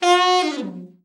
ALT FALL  13.wav